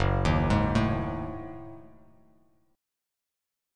F#m6add9 chord